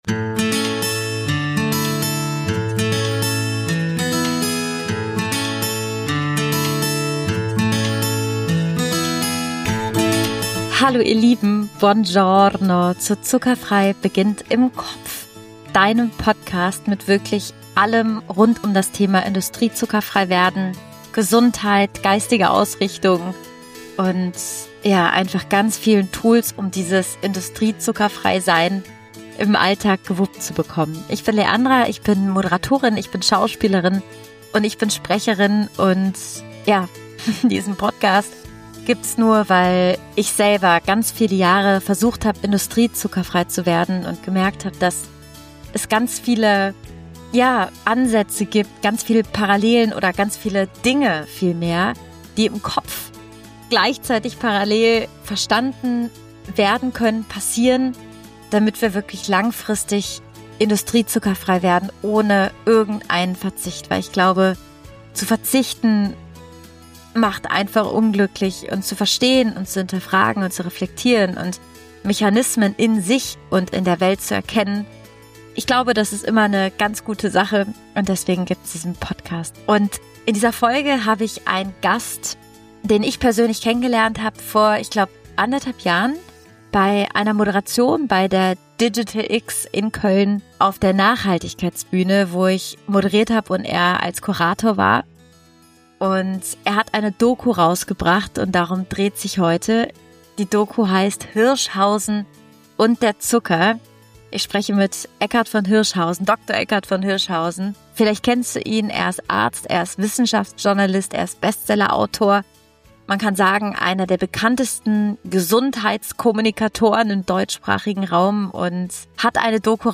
Gesunde Erde, gesunde Menschen – und was Zucker damit zu tun hat -Interview mit Dr. Eckart von Hirschhausen ~ Zuckerfrei beginnt im Kopf- Raus aus der Zuckermatrix.